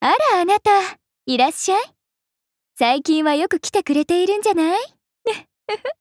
莎拉普通登录语音.mp3